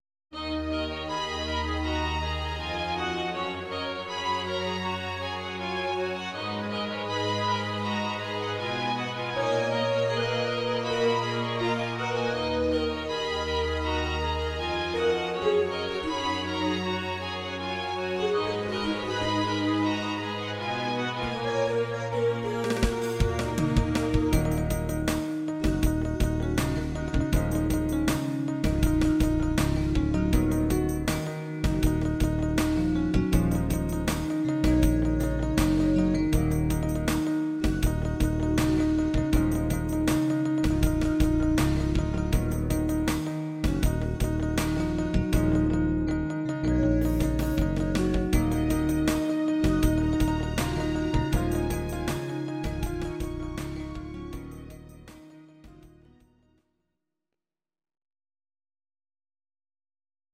Audio Recordings based on Midi-files
German, 1990s